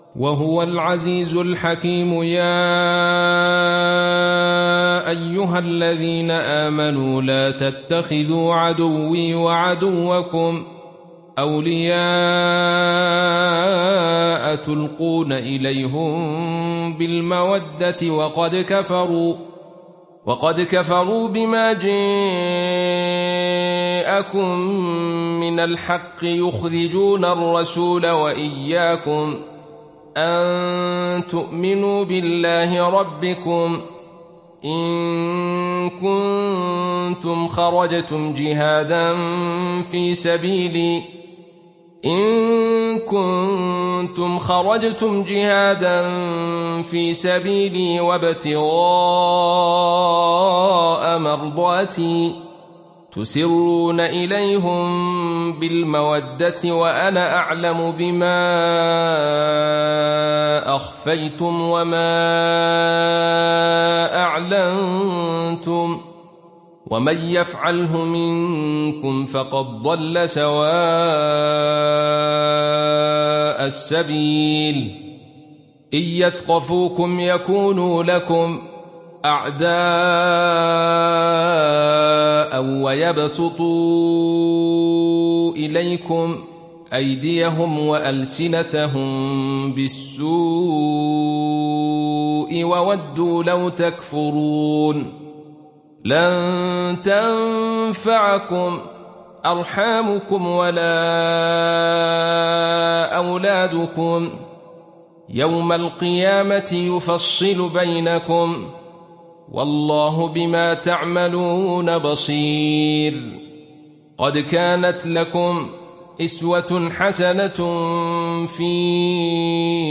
Surat Al Mumtahinah Download mp3 Abdul Rashid Sufi Riwayat Khalaf dari Hamza, Download Quran dan mendengarkan mp3 tautan langsung penuh